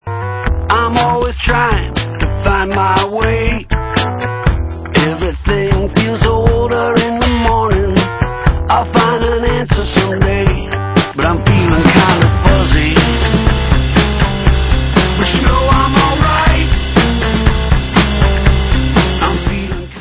indie-rocková kapela